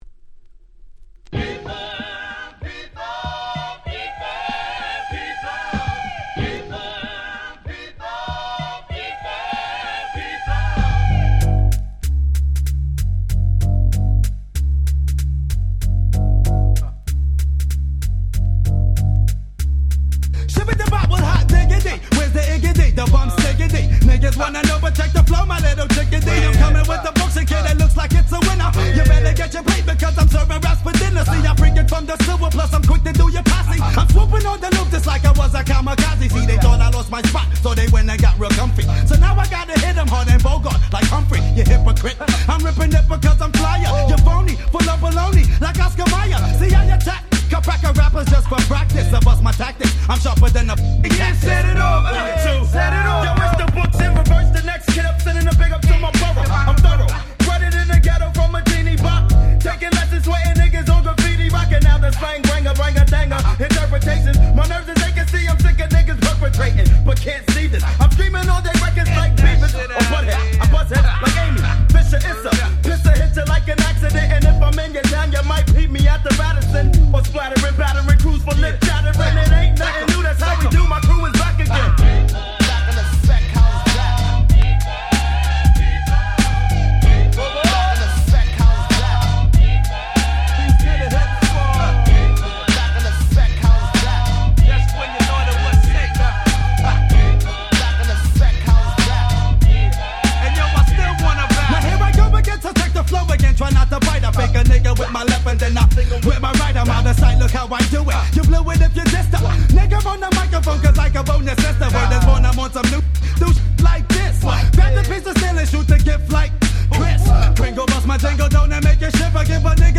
93' Smash Hit Hip Hop !!
彼らのClassicsの中では比較的いぶし銀な1曲。
Boom Bap ブーンバップ